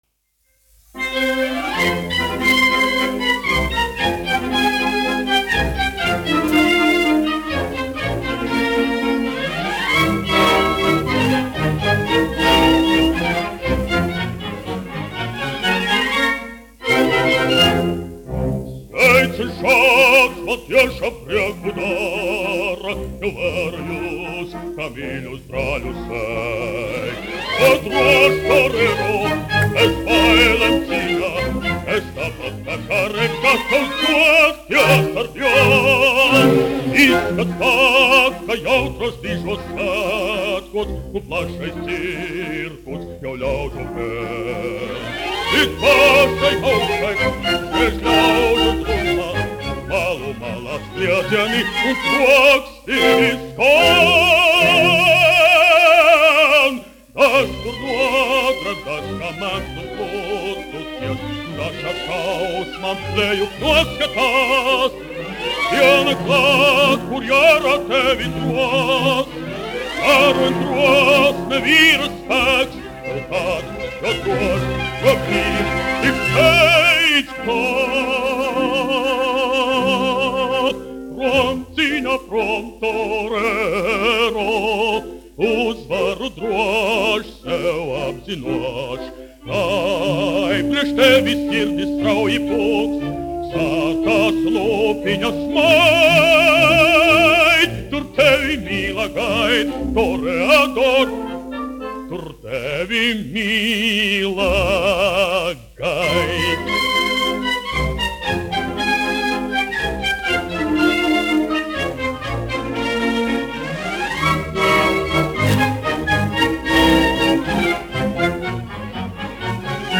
Kaktiņš, Ādolfs, 1885-1965, dziedātājs
1 skpl. : analogs, 78 apgr/min, mono ; 30 cm
Operas--Fragmenti
Latvijas vēsturiskie šellaka skaņuplašu ieraksti (Kolekcija)